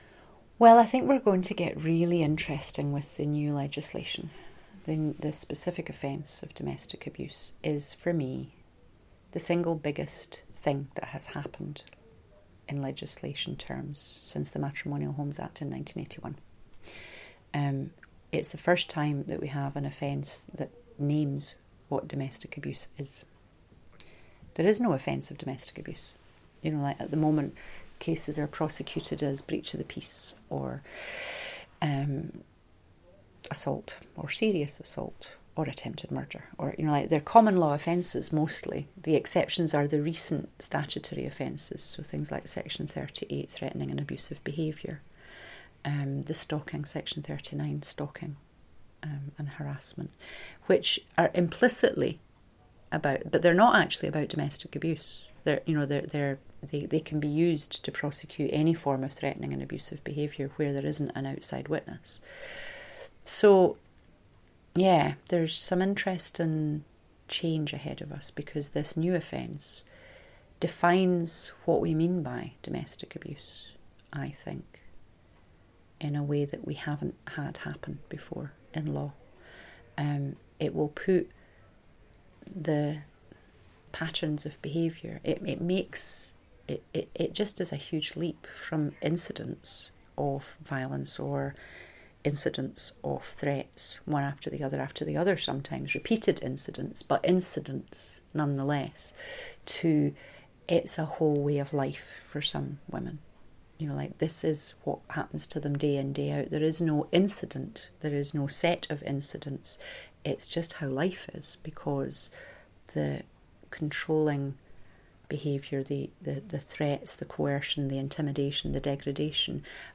Speaking Out: Oral history clips
The Speaking Out project is gathering oral history interviews with women connected to the Women’s Aid movement, both past and present.